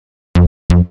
New Bassline Pack